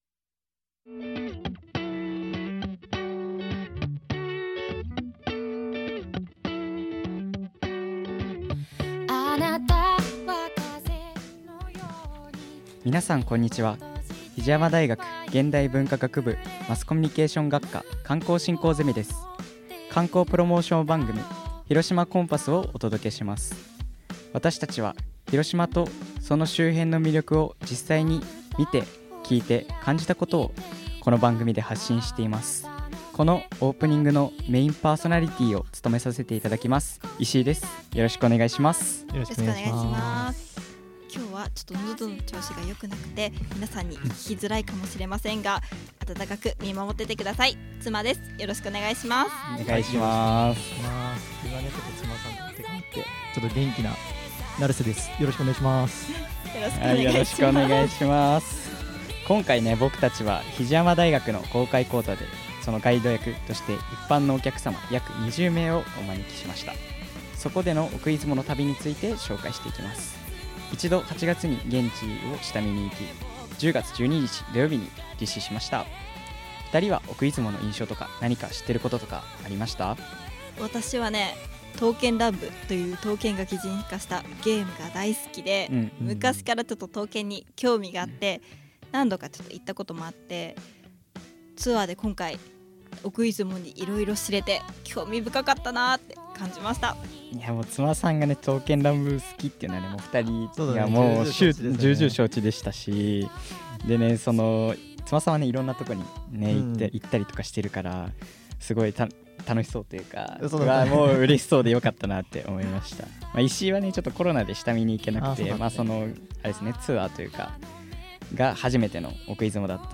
比治山大学presents 観光プロモーションラジオ番組「広島コンパス2024」